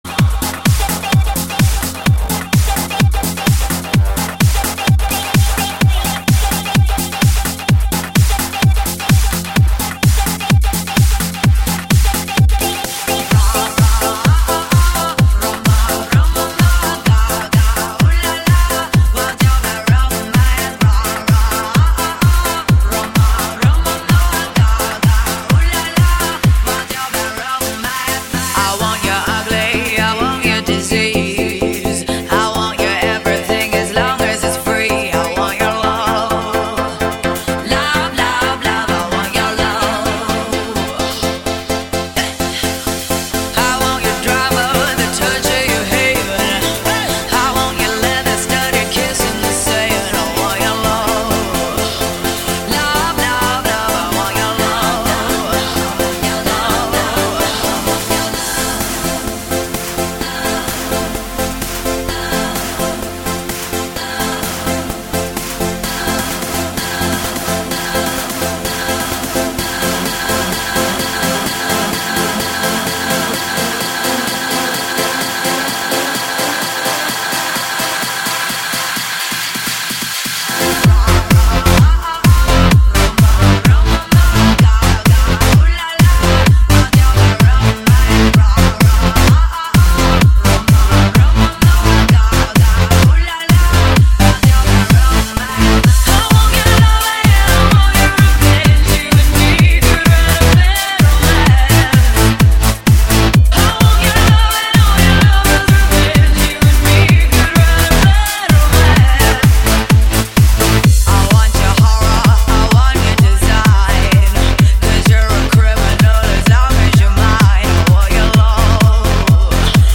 Стиль: Electro House